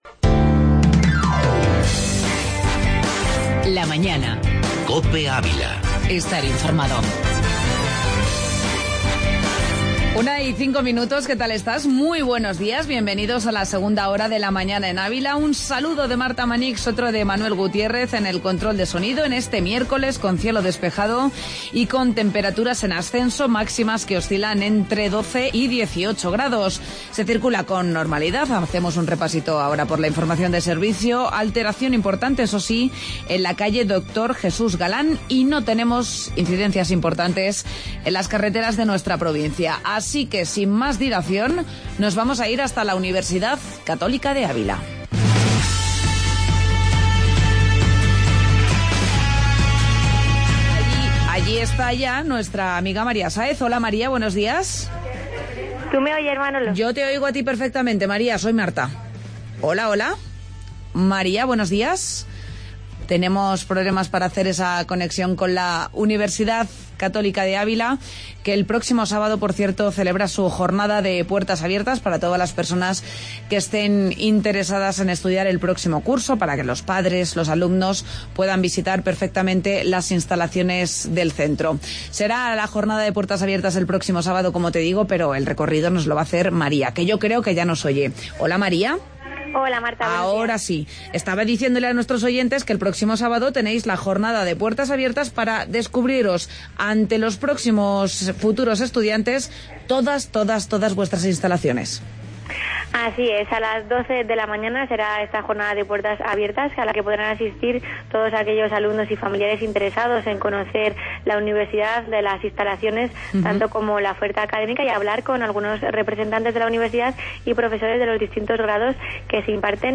AUDIO: Garbantel y Tertulia deportiva